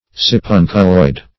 Search Result for " sipunculoid" : The Collaborative International Dictionary of English v.0.48: Sipunculoid \Si*pun"cu*loid\, a. [NL.
sipunculoid.mp3